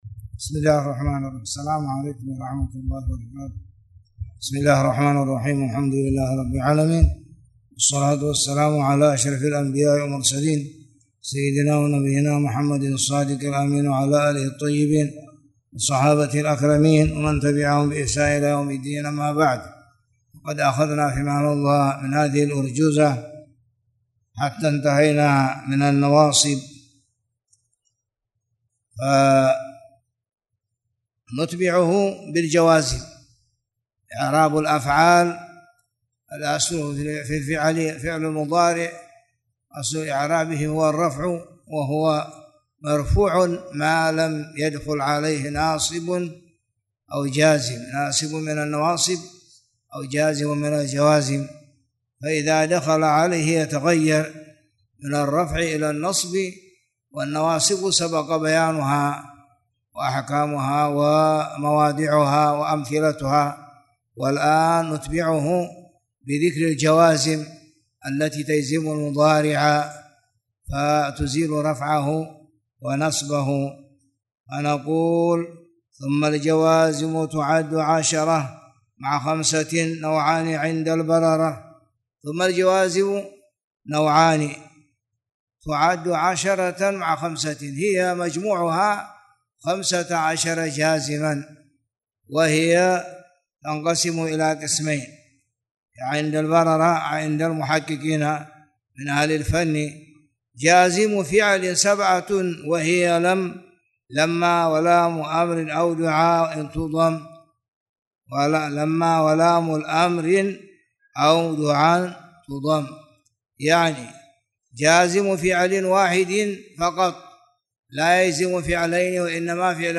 تاريخ النشر ٢٩ ربيع الأول ١٤٣٨ هـ المكان: المسجد الحرام الشيخ